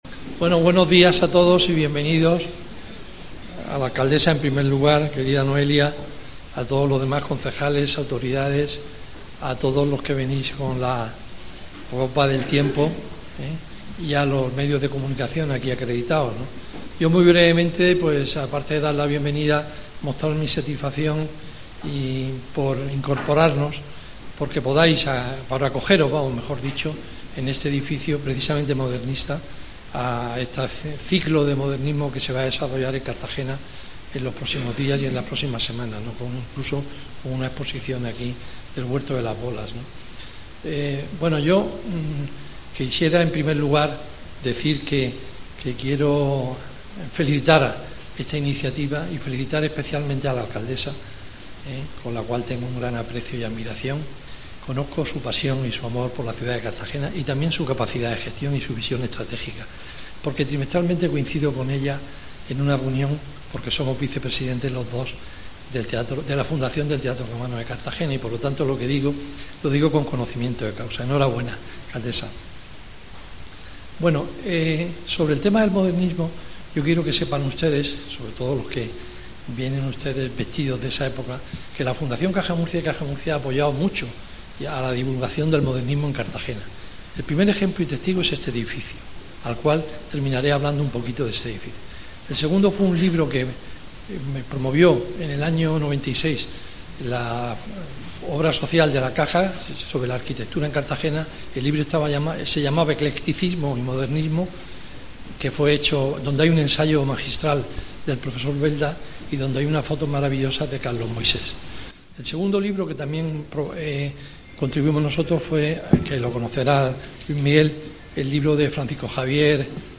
Audio: Presentaci�n de Cartagena Modernista (MP3 - 11,41 MB)